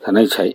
Cdo-fzho_37_(săng-sĕk-chék).ogg